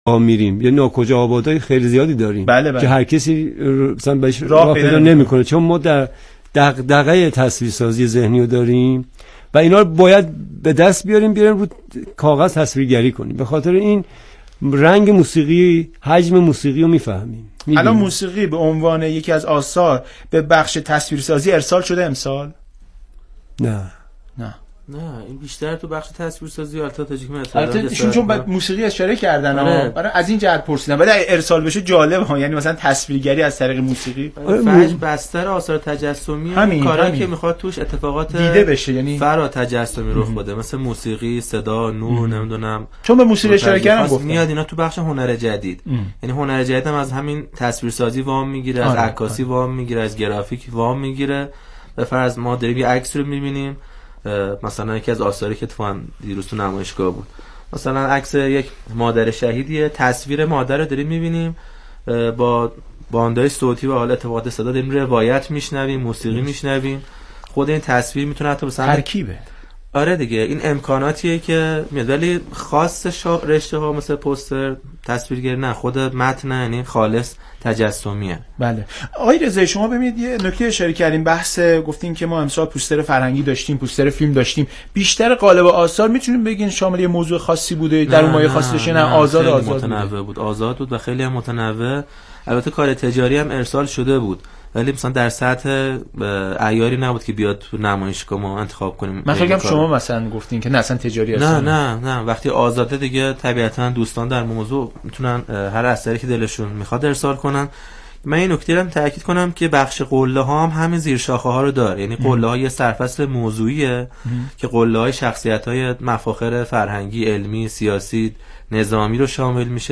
* حضور در استودیو پخش زنده "رادیو گفتگو" | گفتگوی فرهنگی | چهارشنبه ۱۱ بهمن ۱۴۰۲